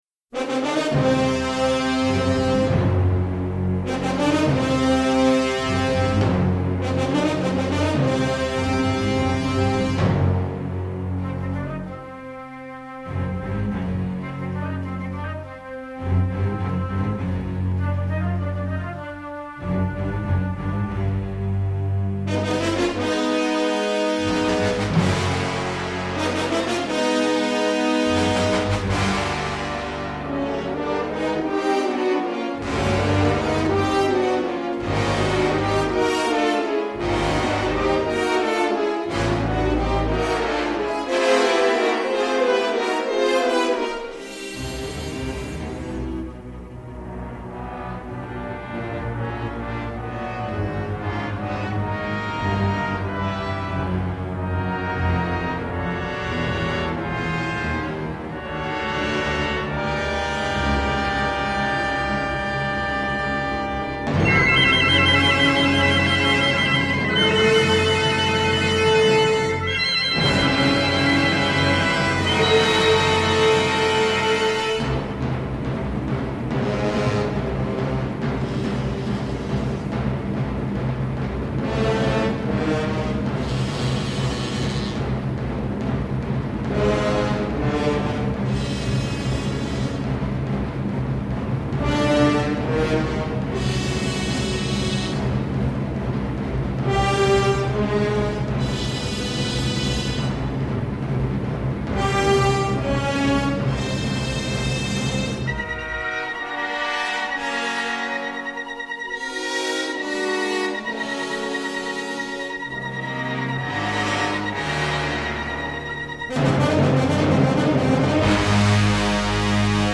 nocturne, implacable, singulière